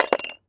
metal_small_movement_05.wav